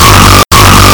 spy_battlecry011.mp3